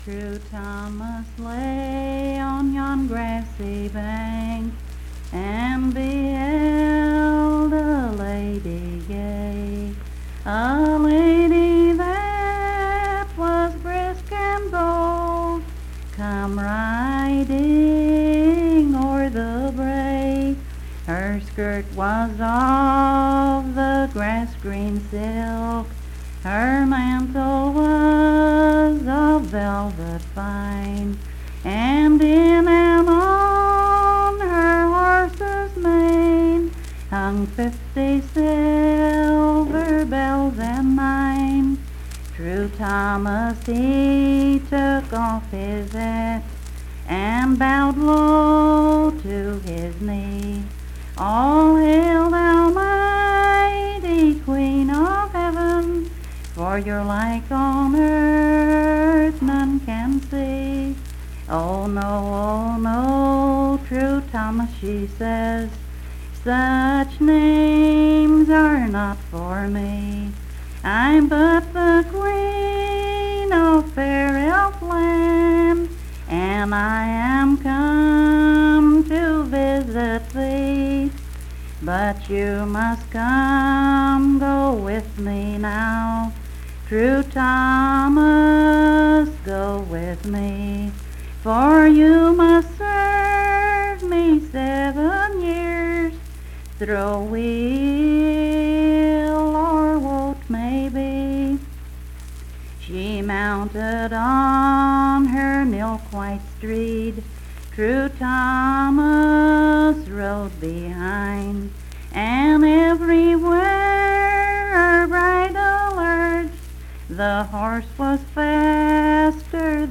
Unaccompanied vocal music
Verse-refrain 16(4).
Performed in Coalfax, Marion County, WV.
Voice (sung)